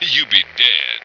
flak_m/sounds/male2/int/M2Yoube.ogg at 098bc1613e970468fc792e3520a46848f7adde96